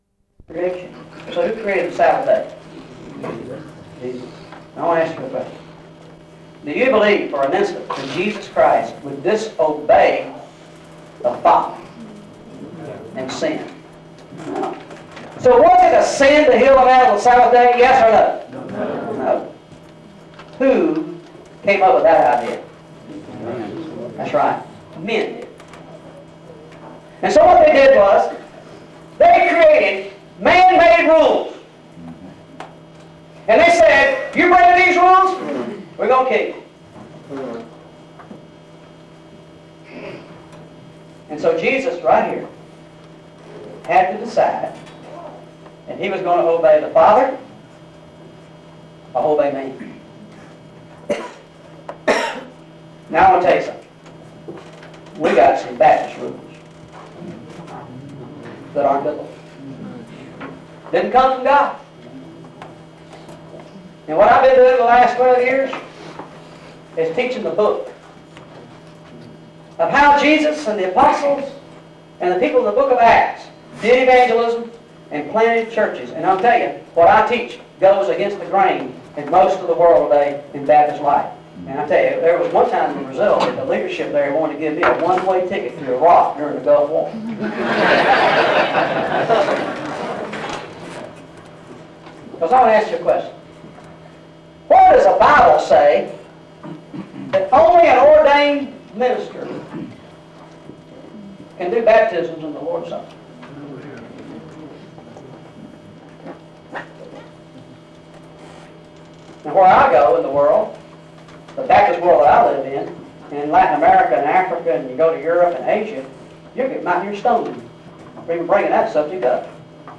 Special Lecture on Harvest Missions